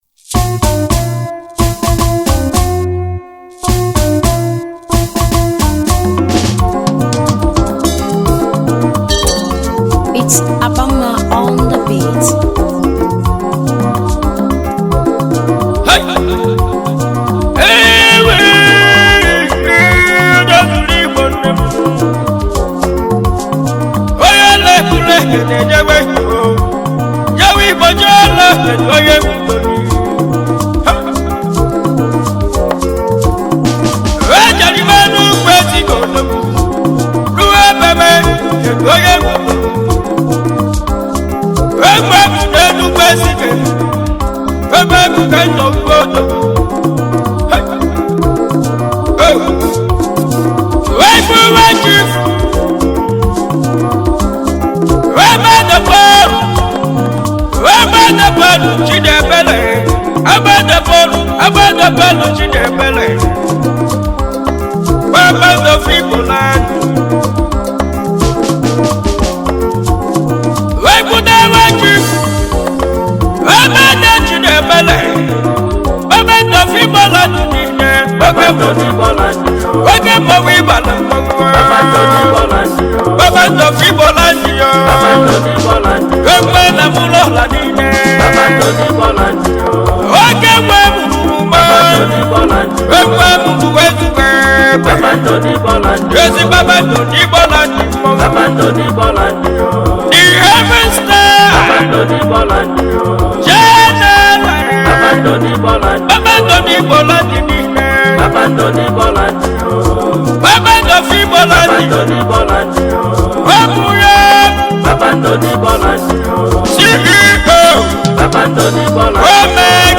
a good highlife tune